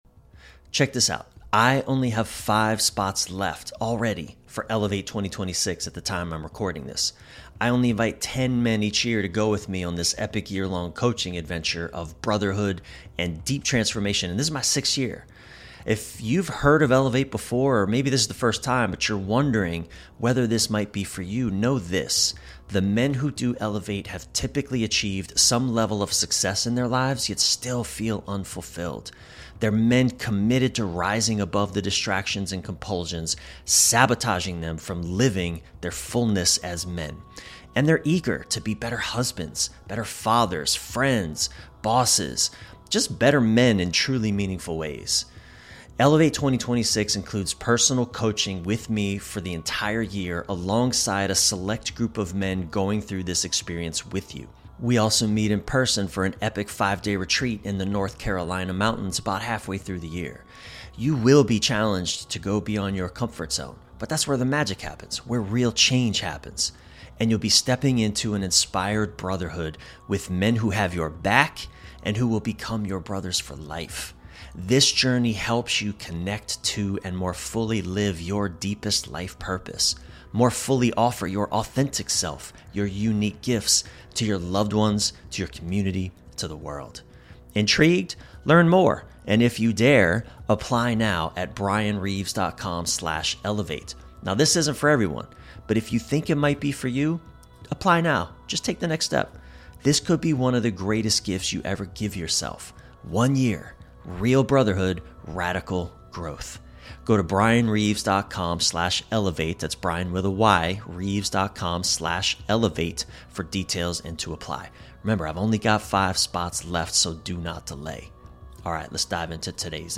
In this special on-the-road episode